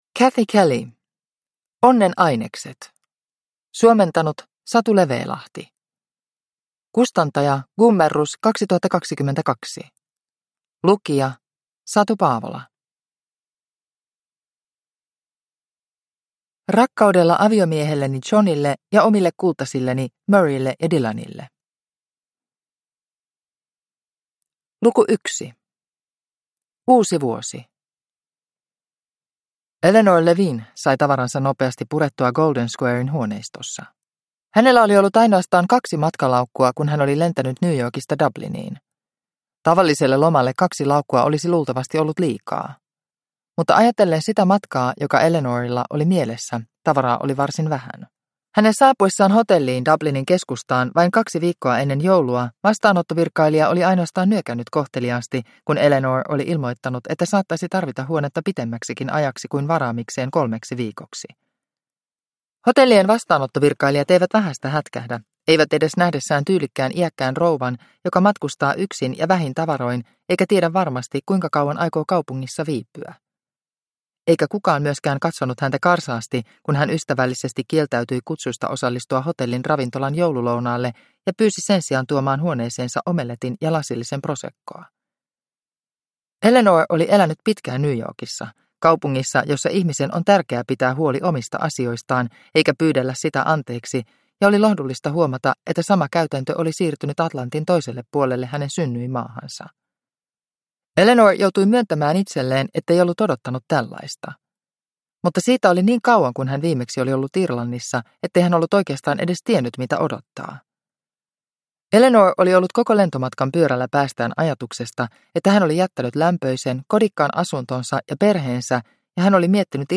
Onnen ainekset – Ljudbok – Laddas ner